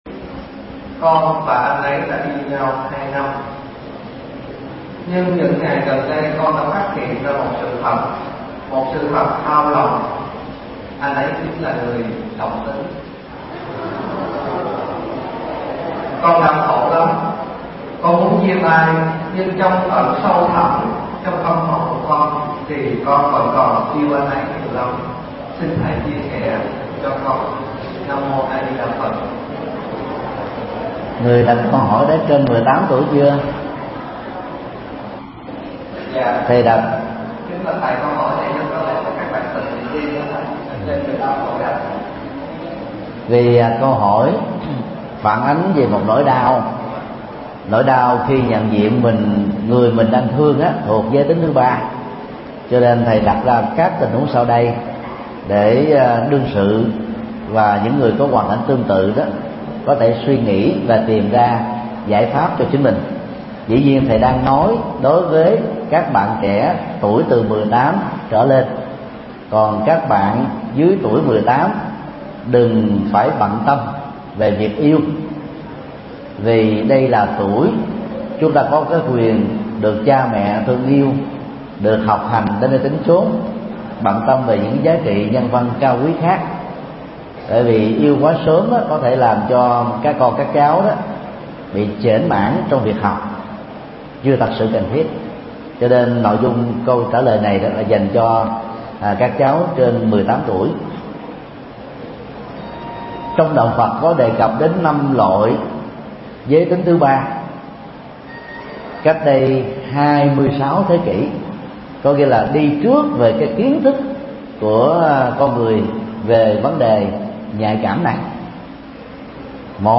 Vấn đáp: Ứng xử khi yêu người đồng tính – Thầy Thích Nhật Từ mp3